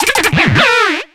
Cri de Castorno dans Pokémon X et Y.